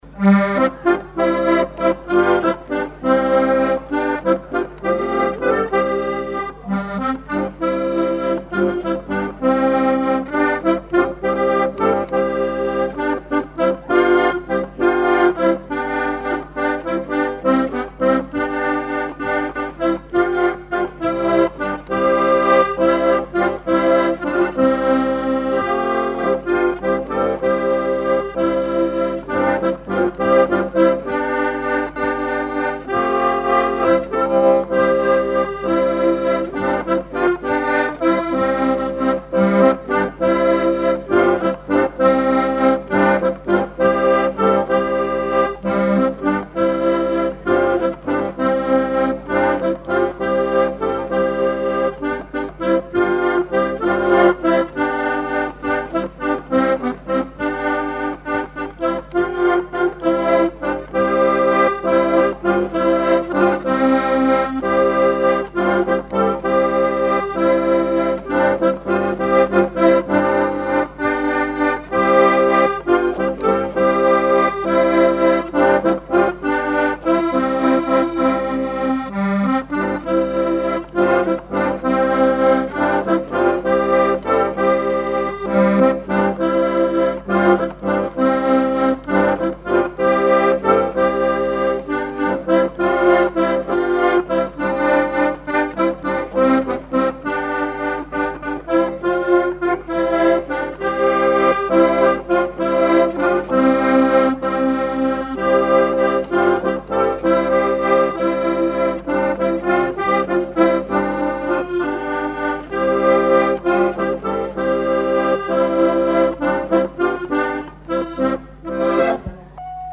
danse : valse
Genre strophique
collectif de musiciens pour une animation à Sigournais
Pièce musicale inédite